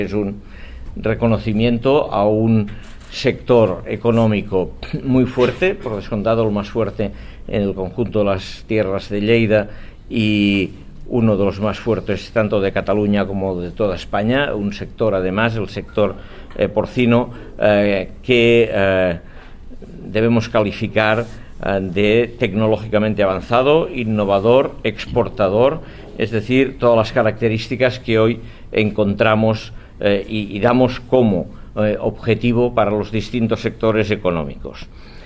tall-de-veu-dangel-ros-sobre-la-importancia-del-sector-porci